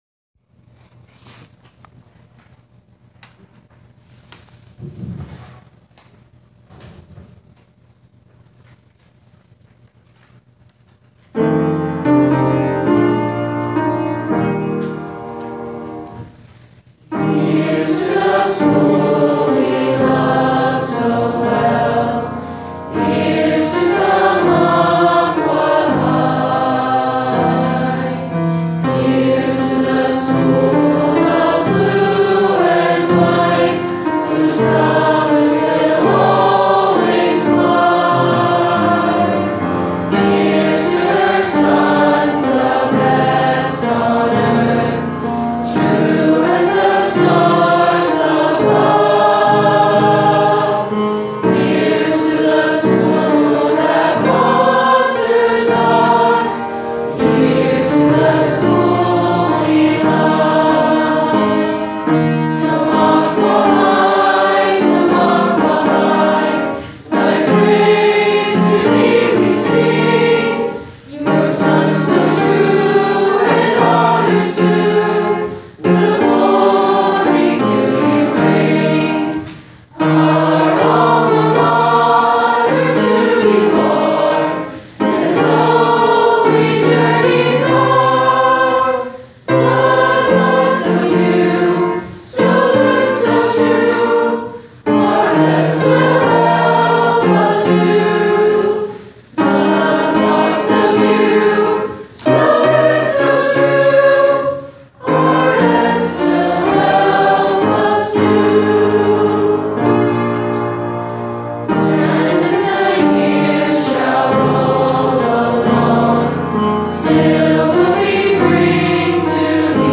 TAHS Alma mater (2018-19 C. Choir) Click on to hear.